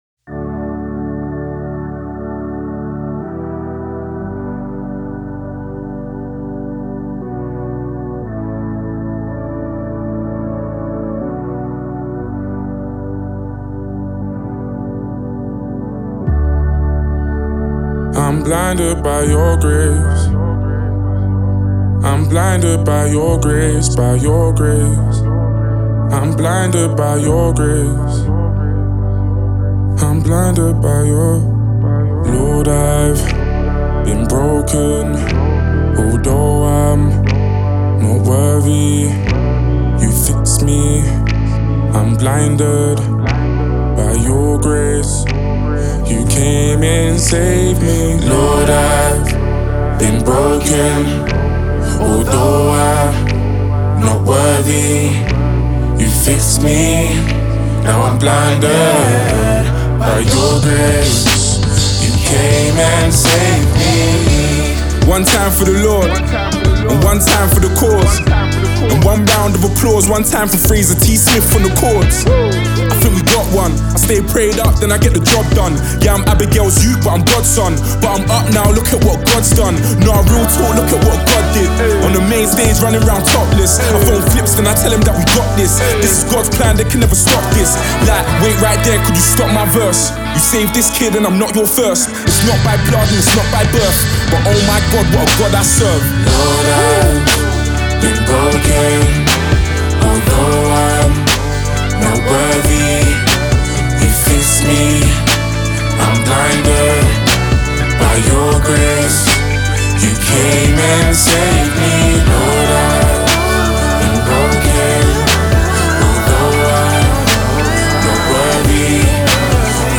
English grime and hip-hop artist